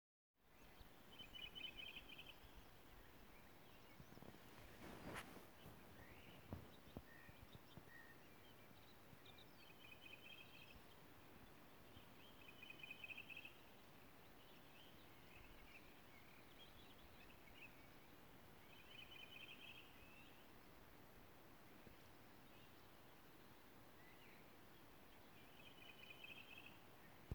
Fuglen har en karakteristisk høy fløytelyd som skiller seg godt ut.
Den har varierende sang, men den høye fløytelyden går igjen. Tok opptaket nå i dag tidlig.
Høres ut som at det er en måltrost midt inne i dette trostekoret, men litt vanskelig å være helt sikker. Det kan også hende at dette er en duetrost.